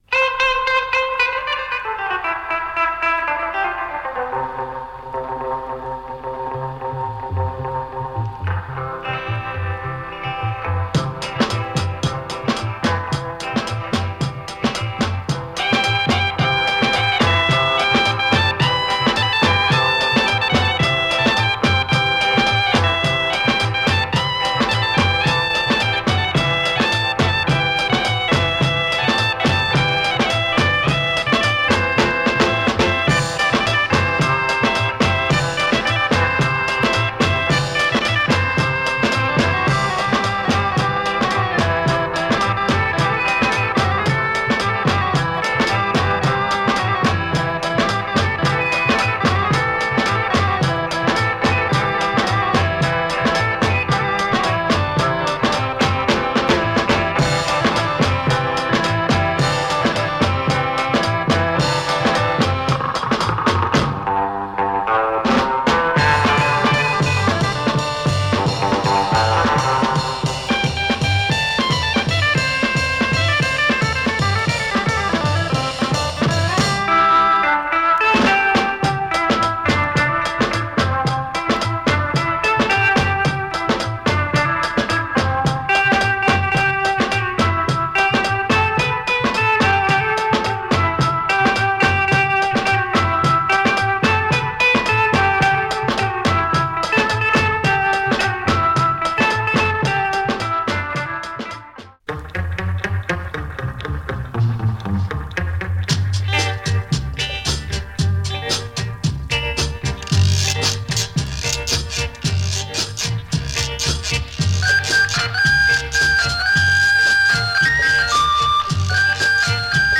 Brillant Pakistan pop, jazz and twist
top exotica tunes from the sub continent